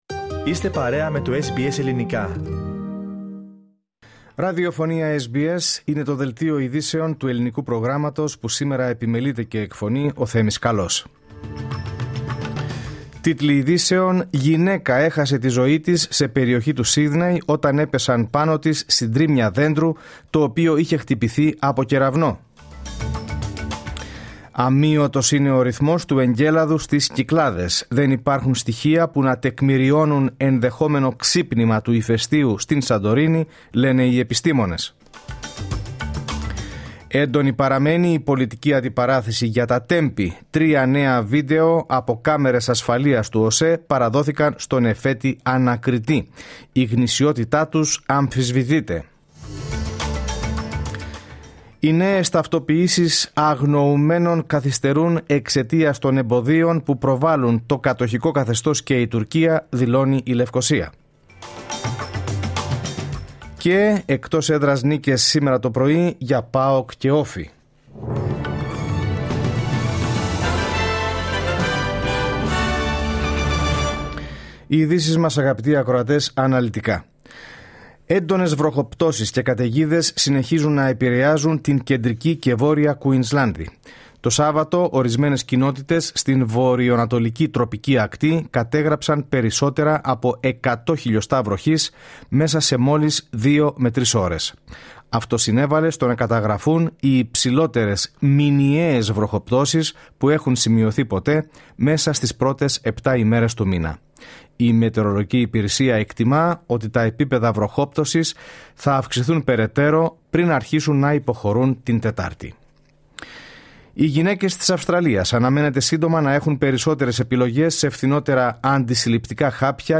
Τίτλοι Ειδήσεων